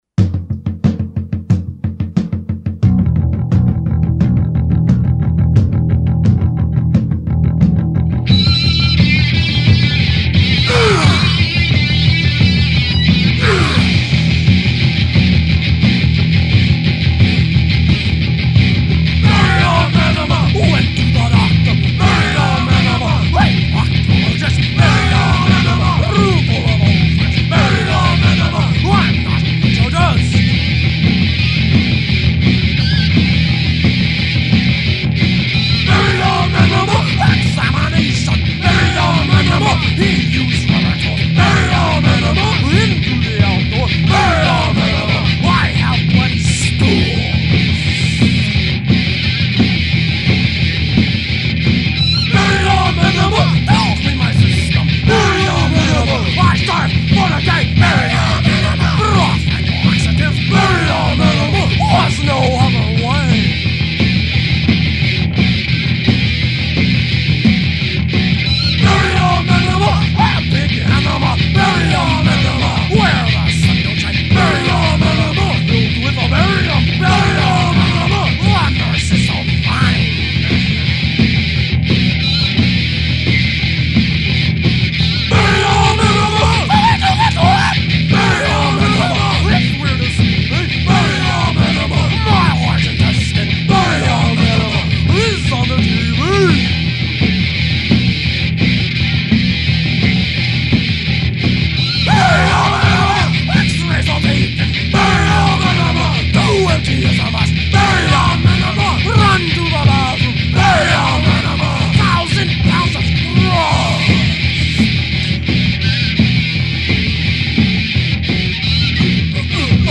recorded on a Tascam Porta-One four track